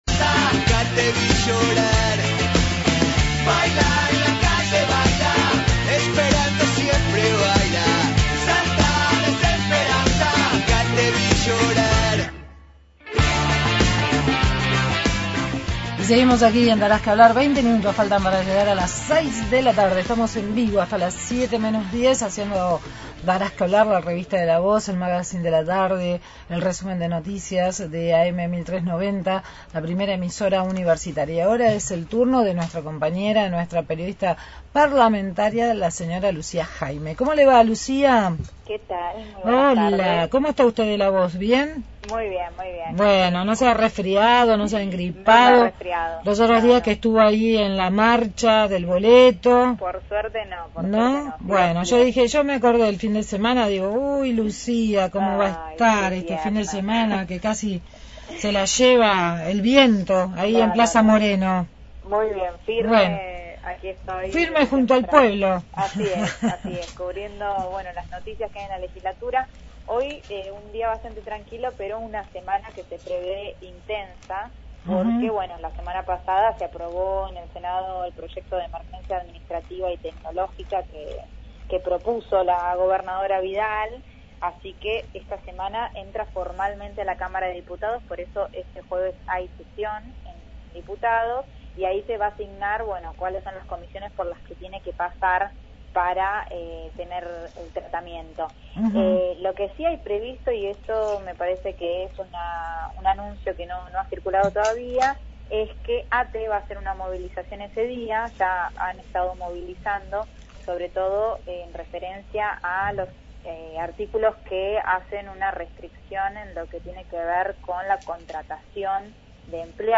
Móvil/ Diputada Alejandra Martínez sobre proyecto para industria del software – Radio Universidad